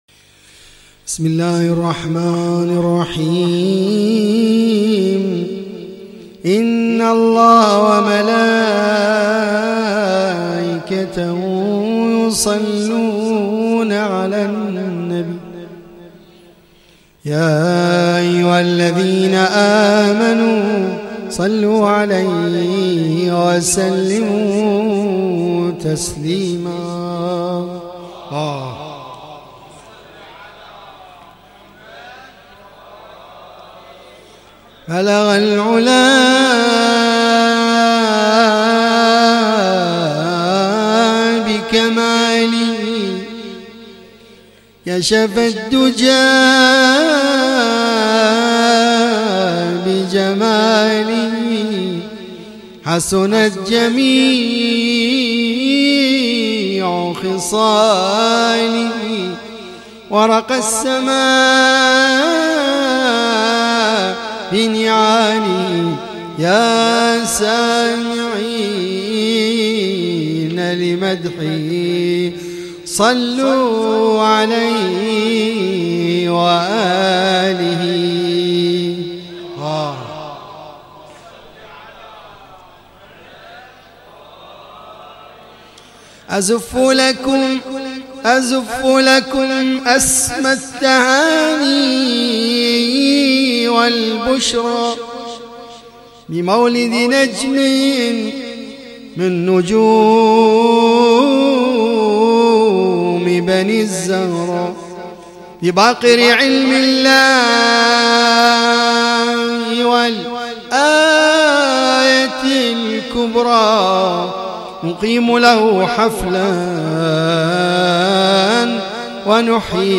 تغطية صوتية: مولد الإمام الباقر ع 1439هـ